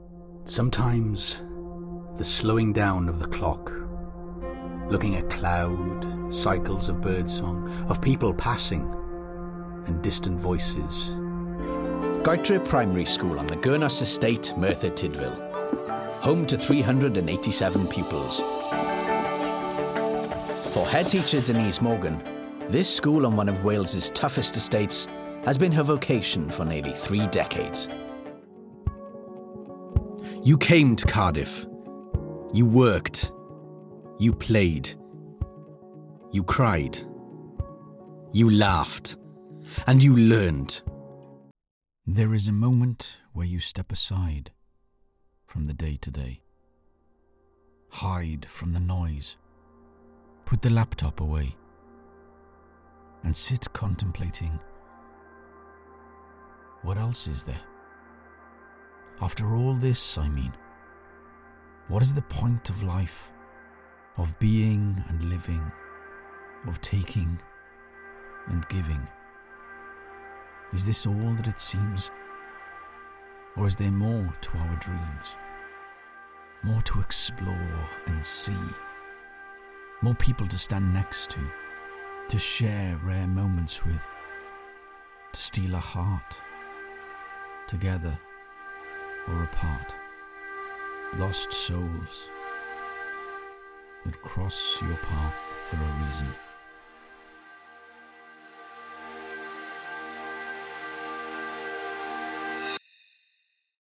Southern Welsh, Northern Welsh, RP, Liverpool,
Middle Aged
DOCUMENTARY VOICEREEL.mp3